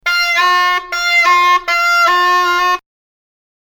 Om det till äventyrs är någon som undrar varför det låter så illa – kan inte musikern som står utanför scenen spela rätt?! – kan vi berätta att det är just så här som Wagner hade tänkt sig.
Faktum är att det krävs en skicklig musiker för att spela något som ska låta så medvetet dåligt.
engelskt horn. Inspelat på Kungliga Operan 3:e januari 2009.
Notera att Wagner skriver ”grell und unrein, zur Nachahmung eines rohen Holzinstrumentes”, och ”sehr grell” dvs ”gällt och orent, efterhärmning av ett rått träinstrument ” och ”mycket gällt”. Vi har klippt bort pauserna för att det ska bli mer sammanhängande och fungera som ringsignal.
siegfried_cor_anglais_sms.mp3